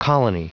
Prononciation du mot colony en anglais (fichier audio)
Prononciation du mot : colony